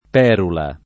[ p $ rula ]